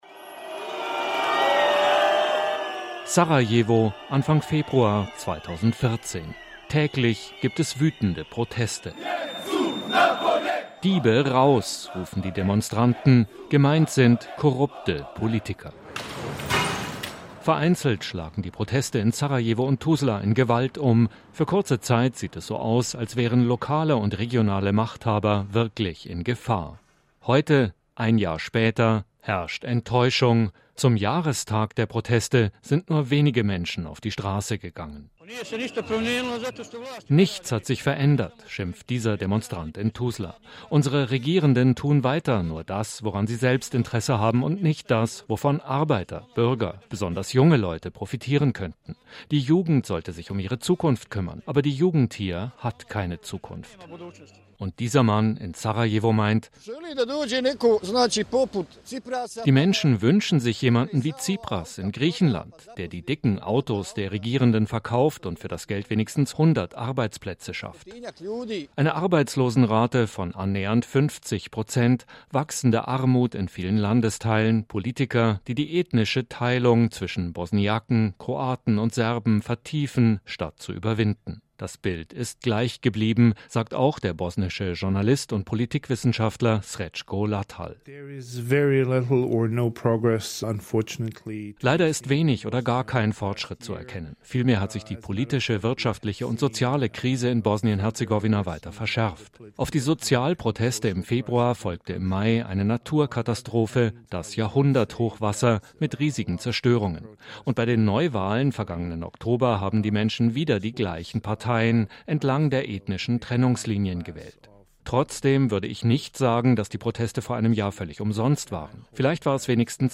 Was ist geblieben von der „revolutionären“ Stimmung in Bosnien-Herzegowina, dem nach wie vor ethnisch geteilten Land mit dem wohl kompliziertesten Regierungssystem der Welt? Wenig – wie enttäuschte Stimmen von Menschen auf der Straße zeigen.